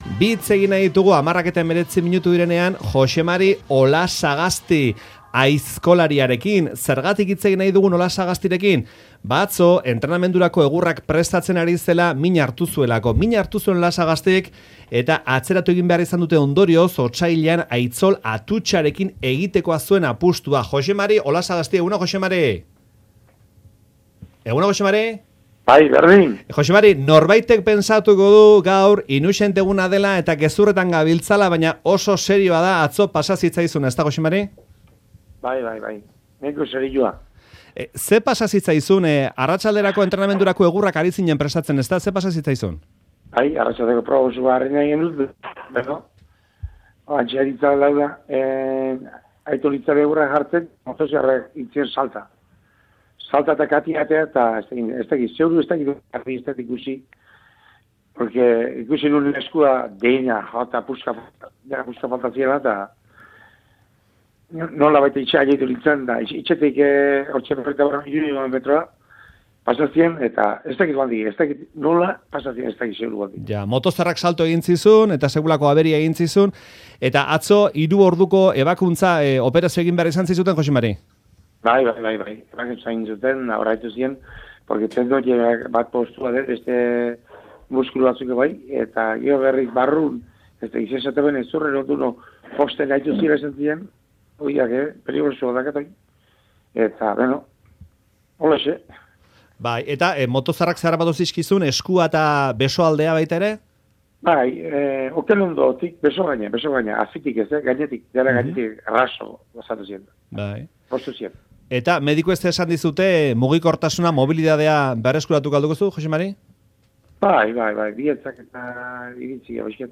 Euskadi Irratiko Faktorian harekin hitz egin dugu